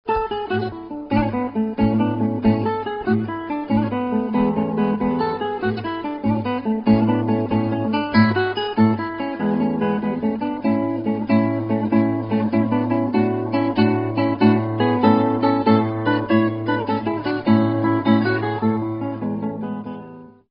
Guitare
Extrait guitare solo
guitare.mp3